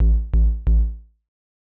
KIN Bass Riff A.wav